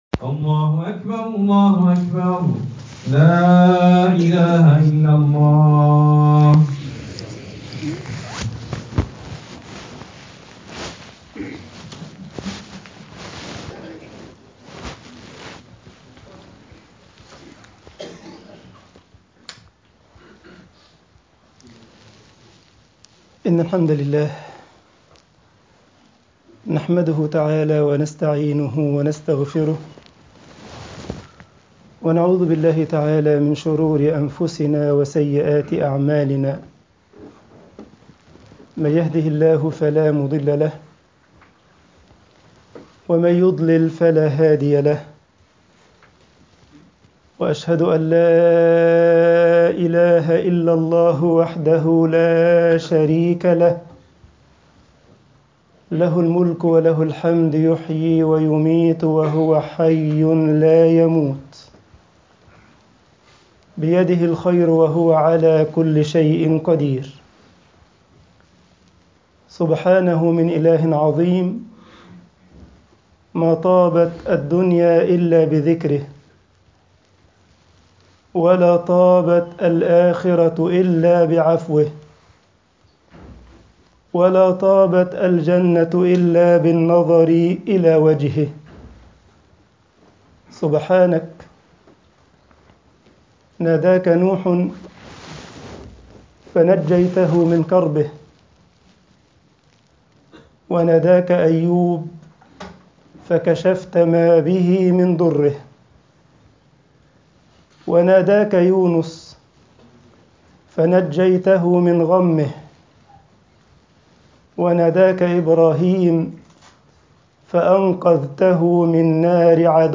خطب الجمعة والعيد